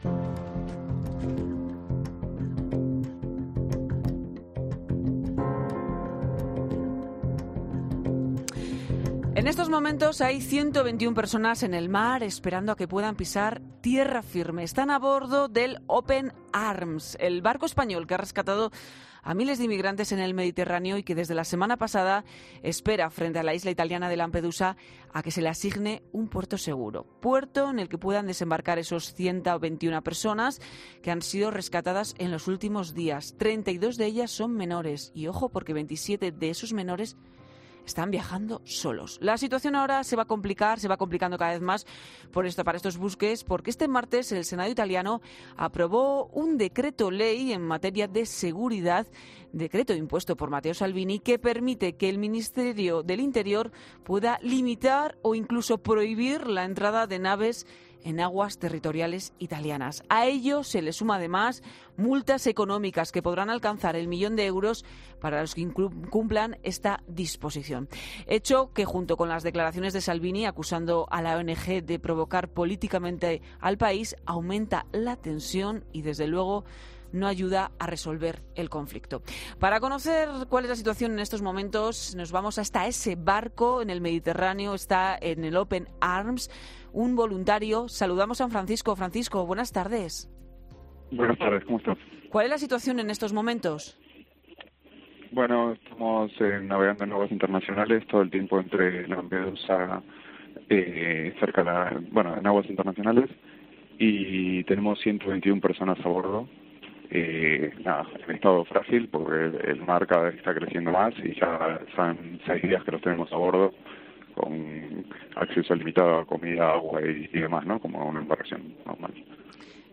uno de los voluntarios que viaja a bordo